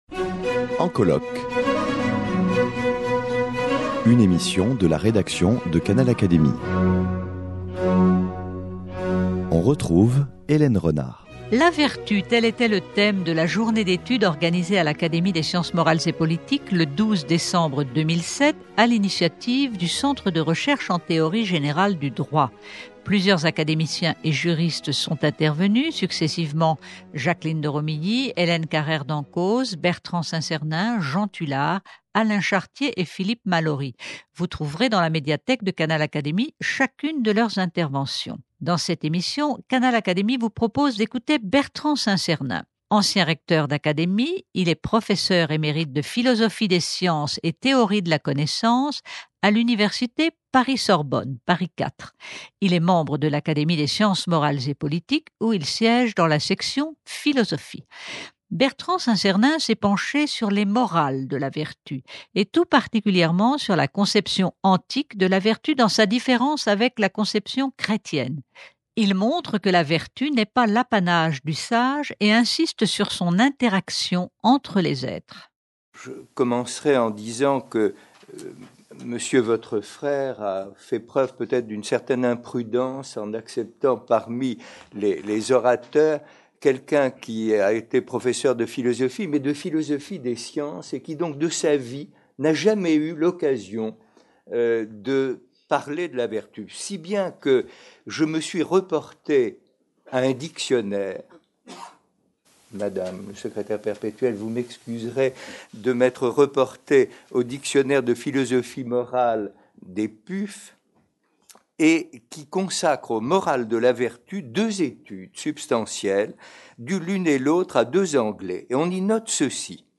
La vertu : tel était le thème de la journée d’étude organisée le 12 décembre 2007 à l’Académie des sciences morales et politiques à l’initiative du Centre de Recherches en Théorie générale du Droit.